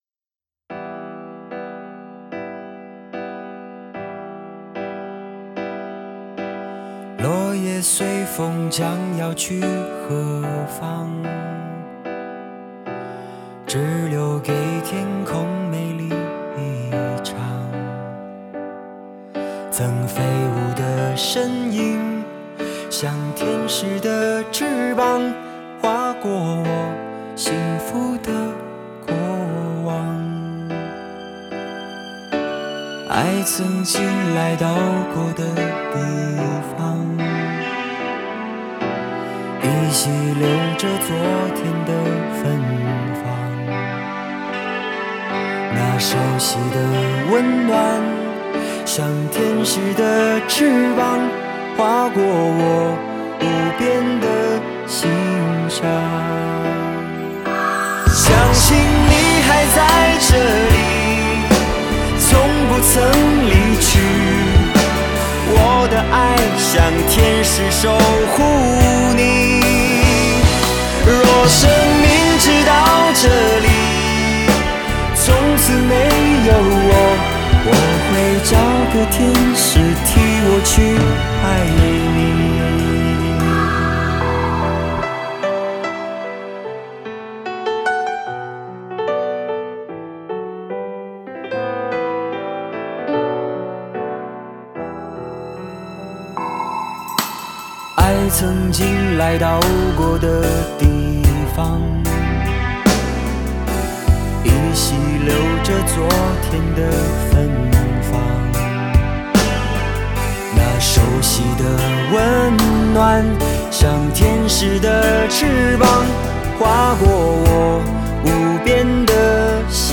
清爽的旋律